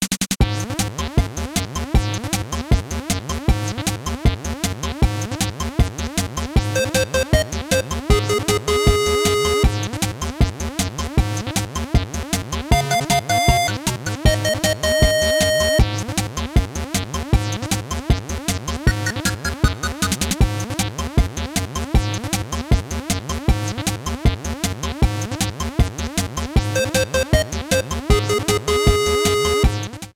Minigame theme